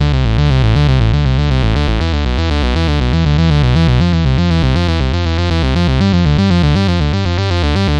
三重低音2
描述：有点厚了。
Tag: 120 bpm Electro Loops Bass Loops 1.35 MB wav Key : D